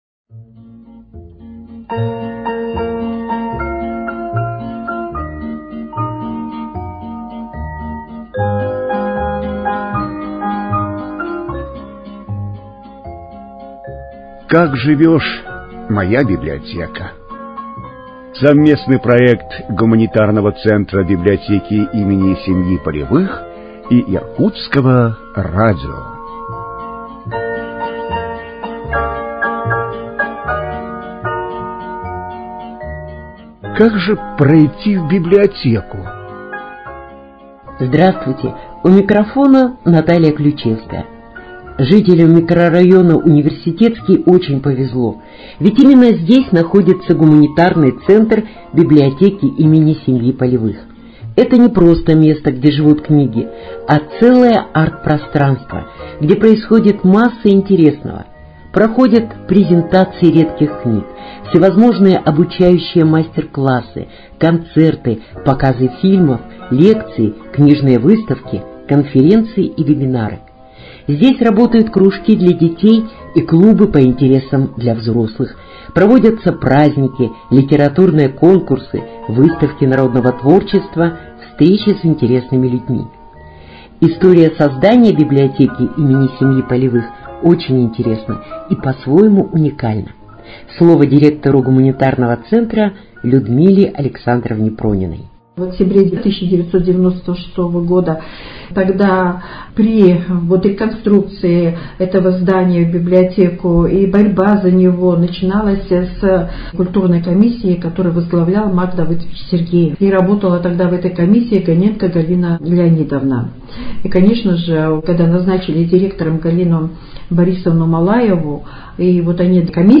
Вы узнаете о новых поступлениях книг, познакомитесь с книжными выставками, услышите голоса любимых писателей, сможете сами принять участие в программе, рассказав о прочитанной вами в детстве первой книжке.